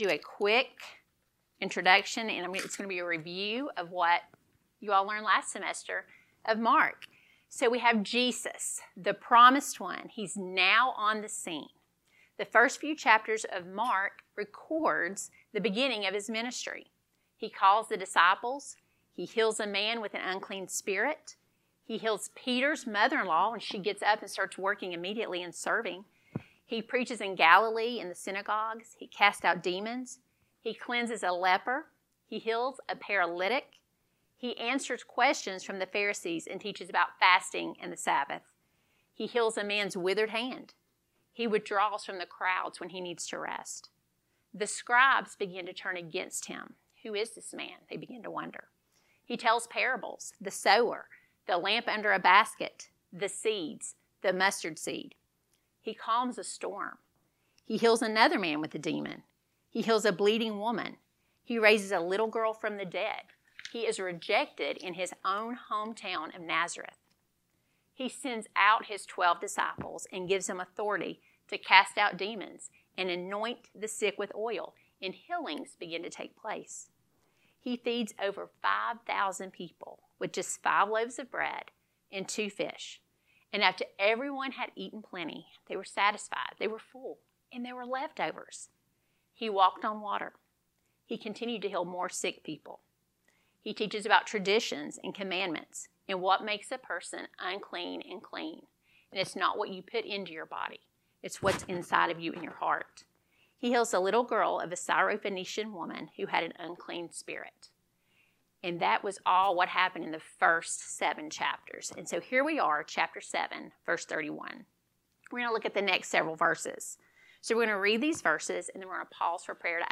Lesson 11